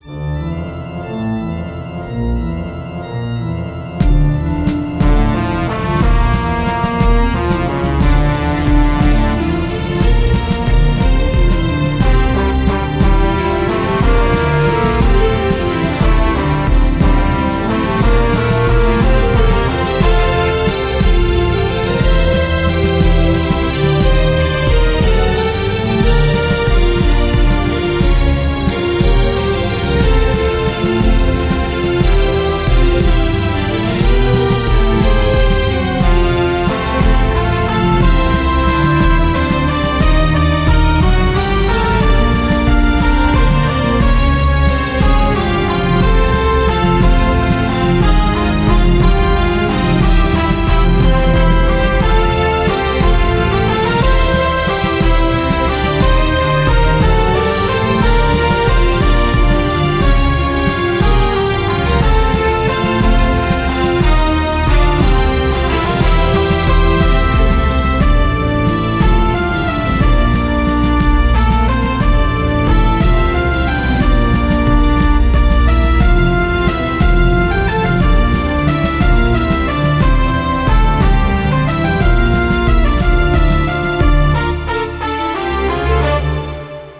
Resumo: Trilha sonora criada para a fase "Pirata"